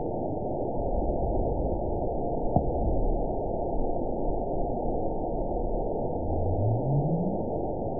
event 911753 date 03/07/22 time 21:34:52 GMT (3 years, 2 months ago) score 9.65 location TSS-AB04 detected by nrw target species NRW annotations +NRW Spectrogram: Frequency (kHz) vs. Time (s) audio not available .wav